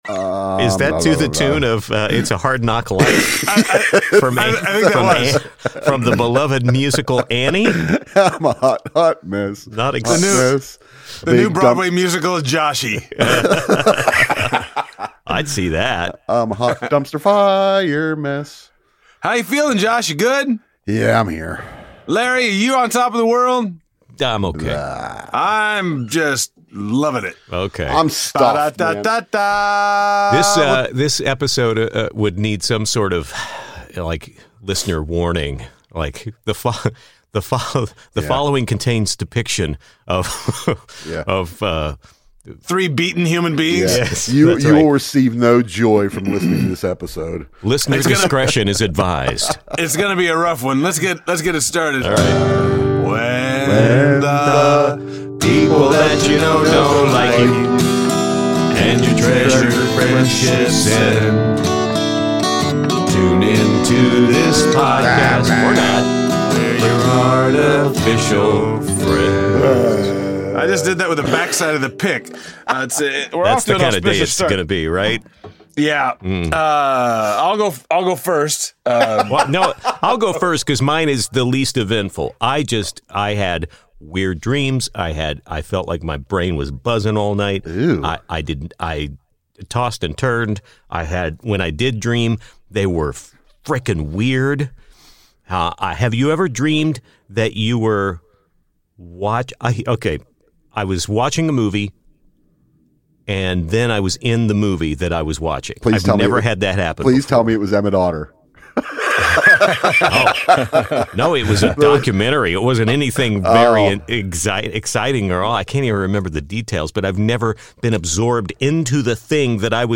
The boys are a little grumpy starting out, but that changes when they review their viewing experience of a classic holiday feature involving river critters.